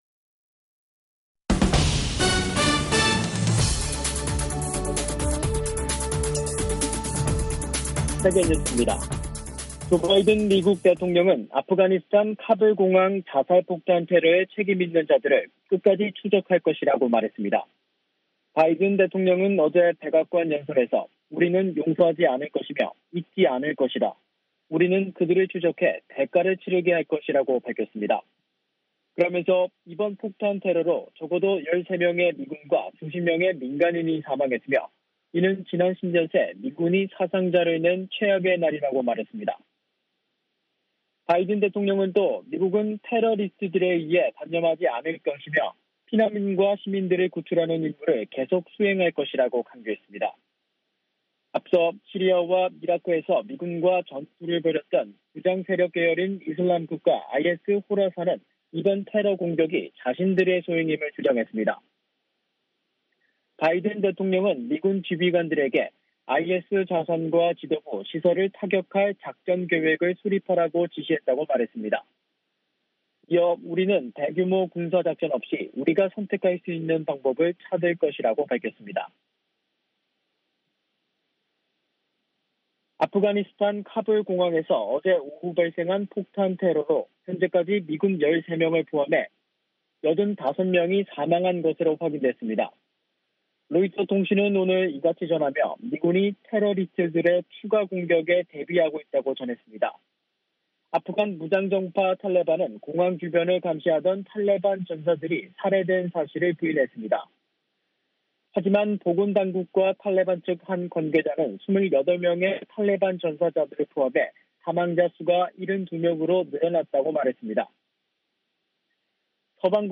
VOA 한국어 간판 뉴스 프로그램 '뉴스 투데이', 2021년 8월 27일 3부 방송입니다. 미국은 북한에서 발사되는 미사일 위협에 대응할 역량을 보유하고 있다고 미 전략사령관이 밝혔습니다. 미 하원 군사위원회가 2022 회계연도 국방수권법안을 확정했습니다.